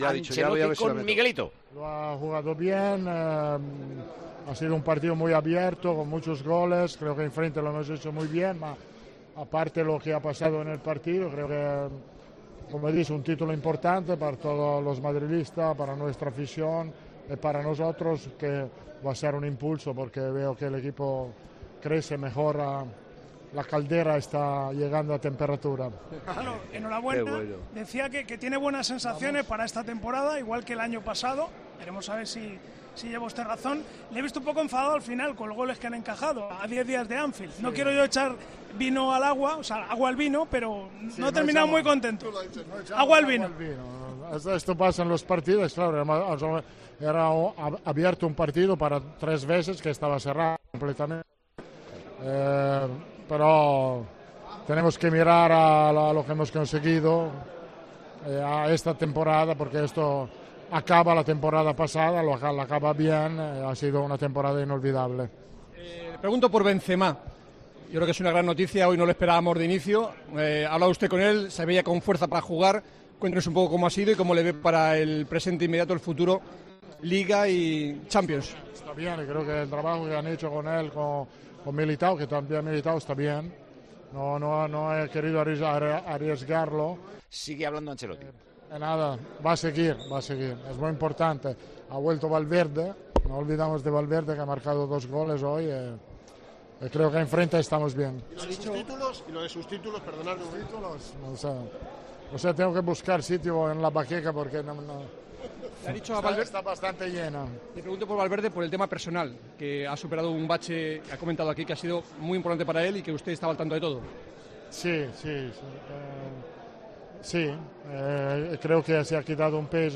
Atención a la prensa